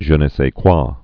(zhə nə sā kwä, sĕ)